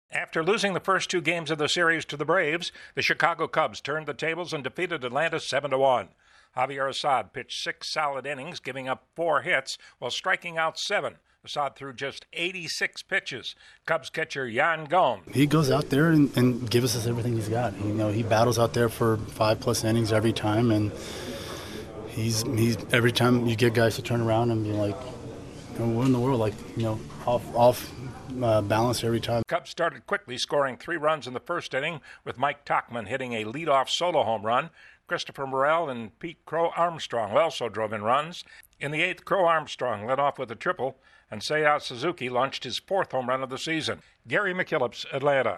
The Cubs avoid a sweep by the Braves. Correspondent